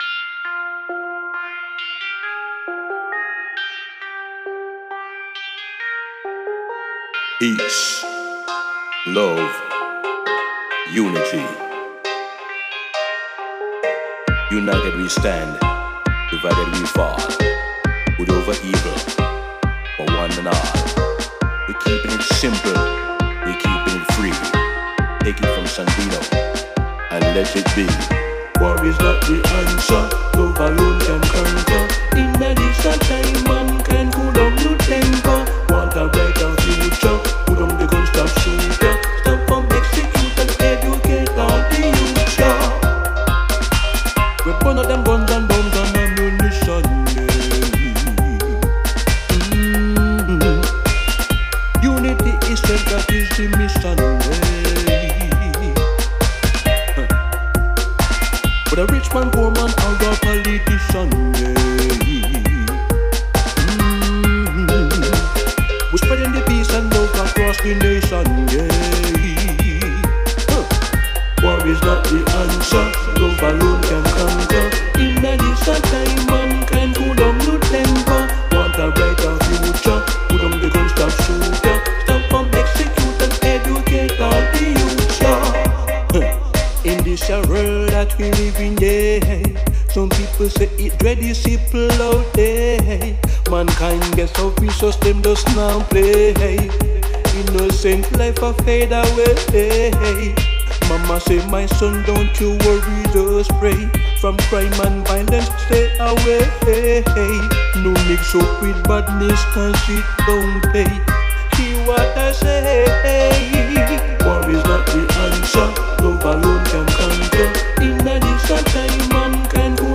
Recorded at CONSCIOUS SOUNDS Studio London UK